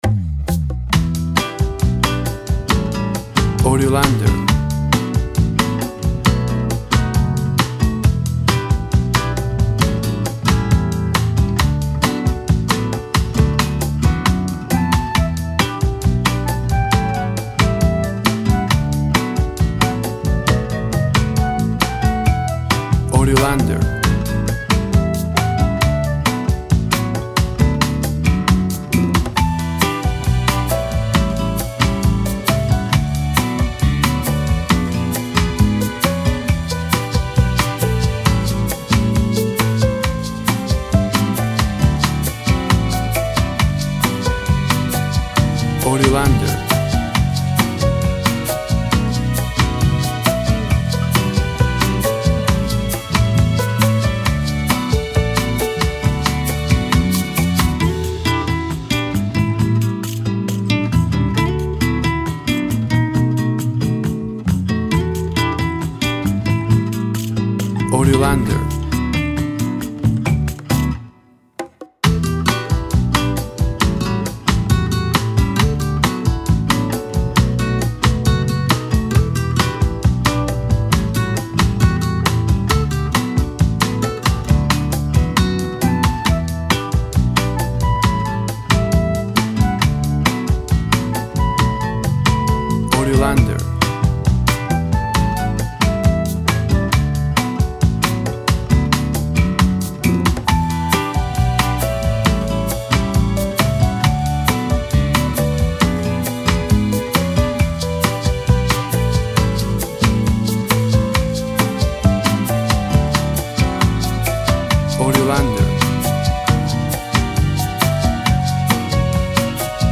Smooth and relaxing
Tempo (BPM) 135